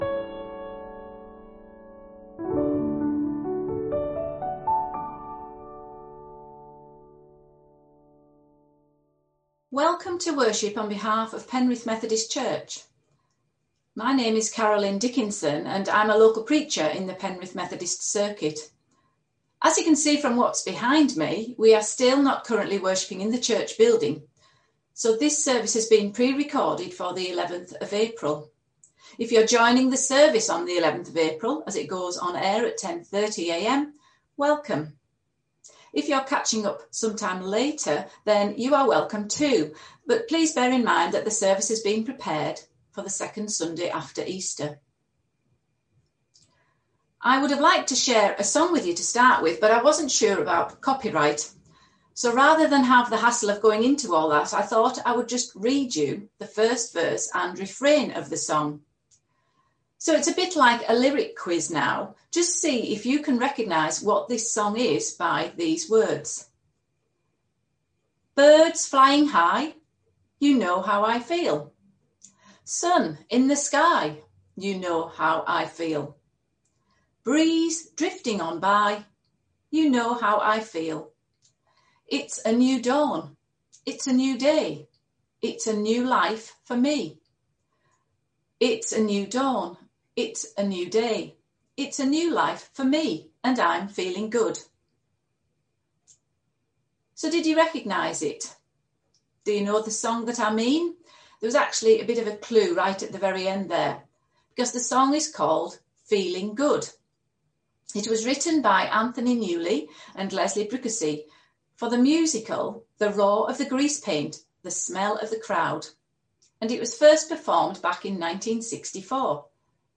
A message from the series
From Service: "10.30am Service"